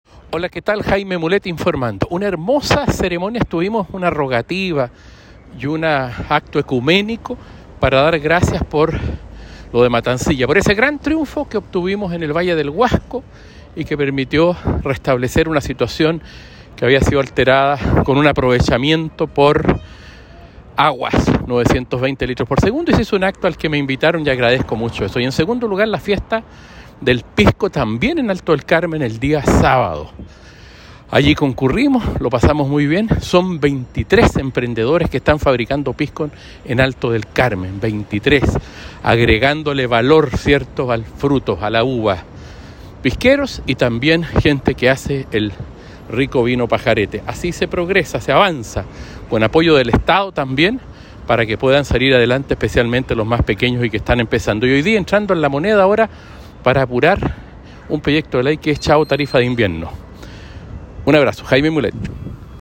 Audio Diputado Jaime Mulet donde informa los siguientes temas: